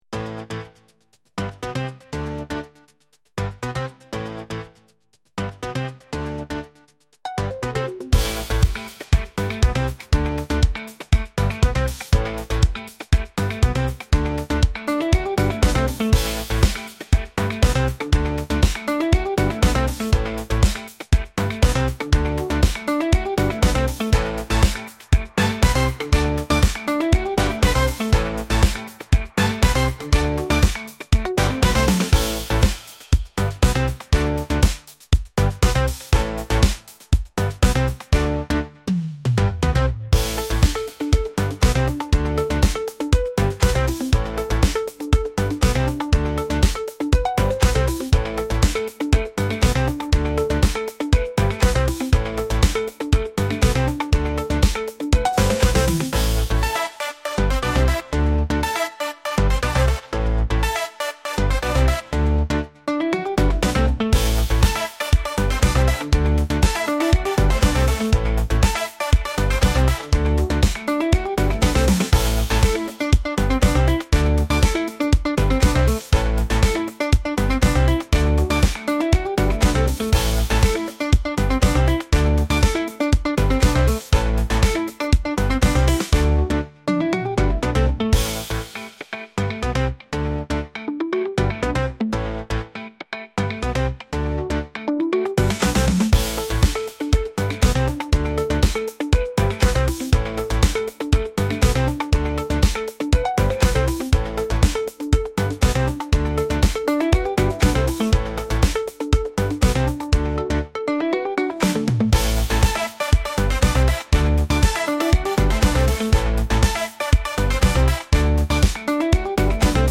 Música del Gimnasio